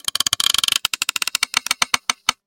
Rope Tension
A thick rope creaking under heavy tension with fiber stress and stretching groans
rope-tension.mp3